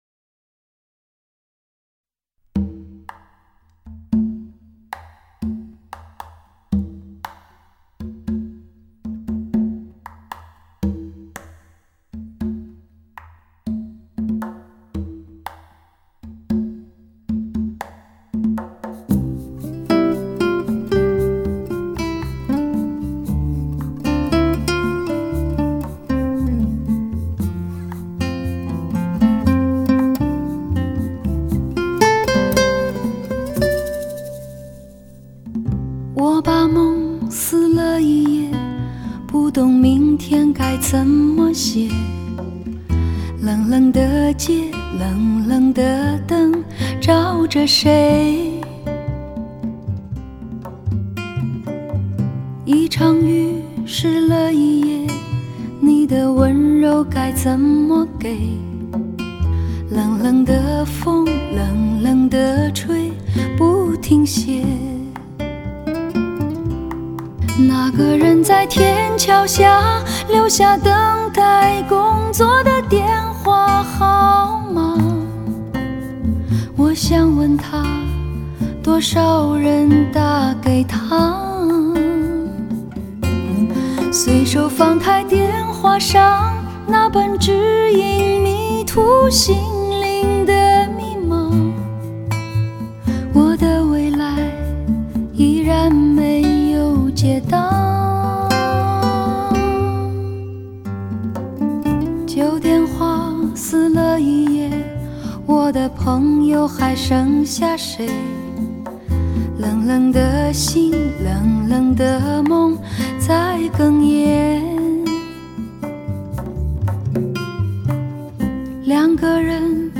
深厚的演唱功底 充满情感的声音媚力 极度磁性的嗓音特质